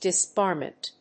/ˌdɪˈsbɑrmʌnt(米国英語), ˌdɪˈsbɑ:rmʌnt(英国英語)/